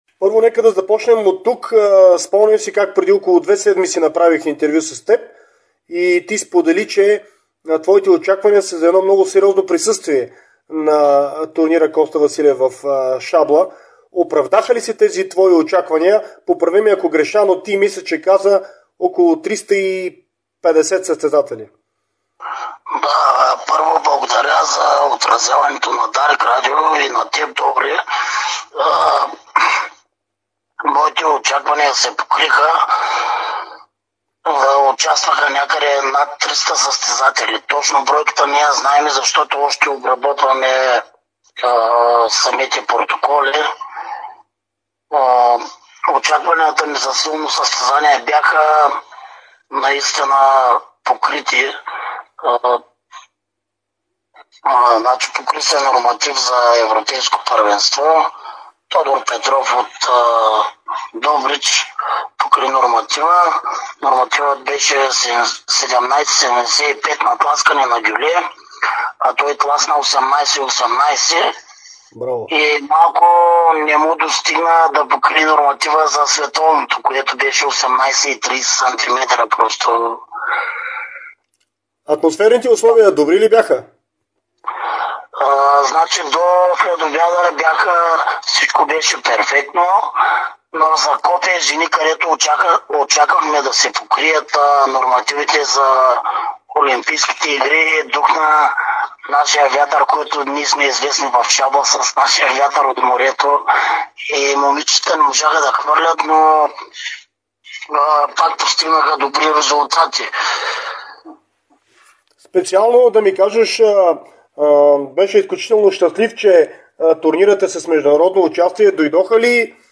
Чуйте цялото интервю с него в приложения звуков файл.